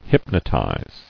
[hyp·no·tize]